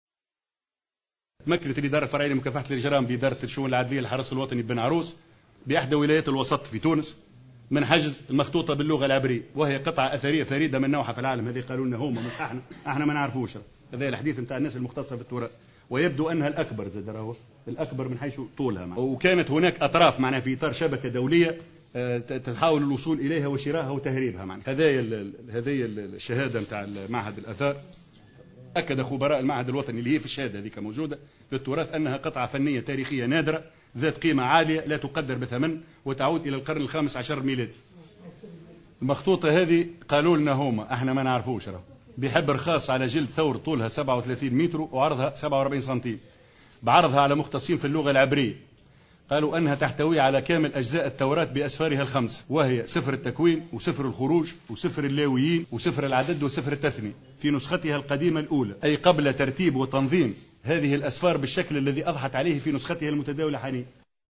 وأكد خلال اللقاء الاعلامي الدوري المنعقد اليوم الجمعة بمقر وزارة الداخلية، تمكن الإدارة الفرعية لمكافحة الإجرام بإدارة الشؤون العدلية للحرس الوطني ببن عروس، من حجز مخطوطة باللغة العبرية بإحدى ولايات الوسط.